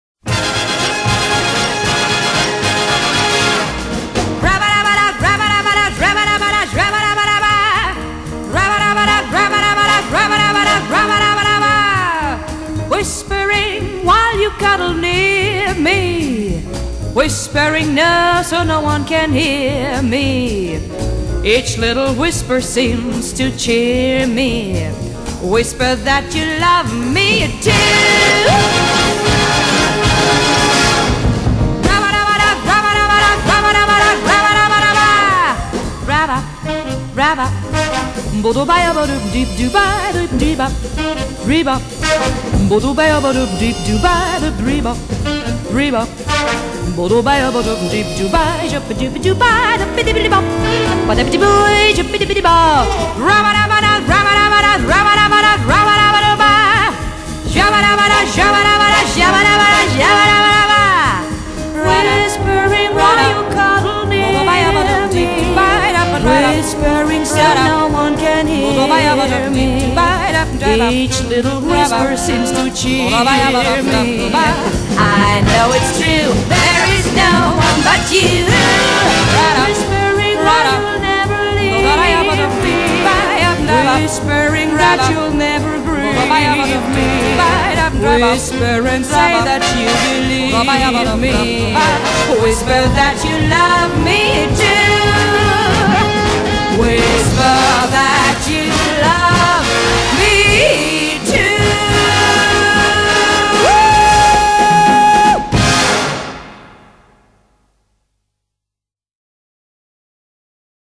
喜欢百老汇爵士曲风的朋友们请勿错过！